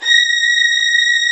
STR STRING0G.wav